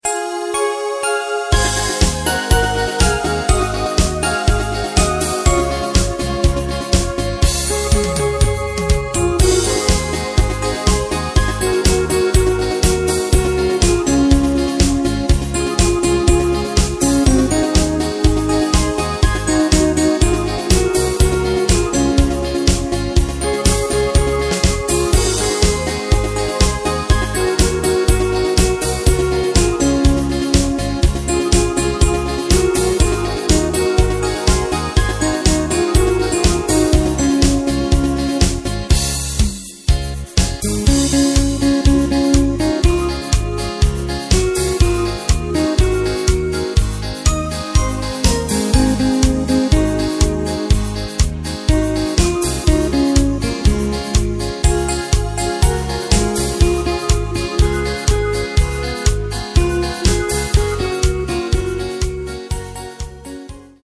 Takt:          4/4
Tempo:         122.00
Tonart:            C#
Schlager aus dem Jahr 2005!